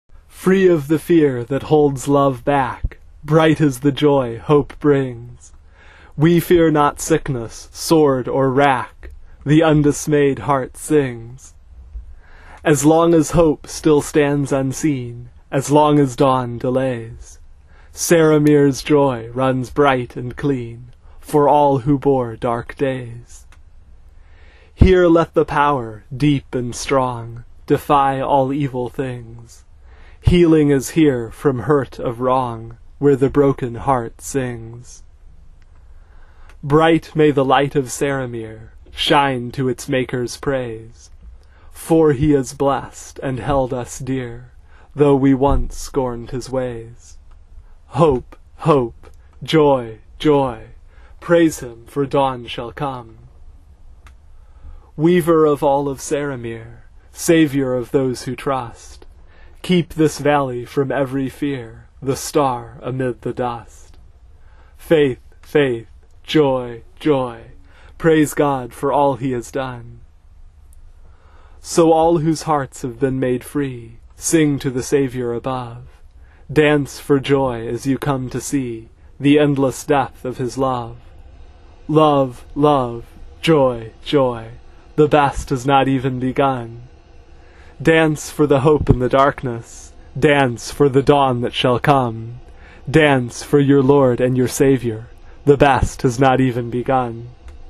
They really should be sung, but I... uh... have a problem with carrying tunes, so for now you will have to settle for the songs read aloud as poems.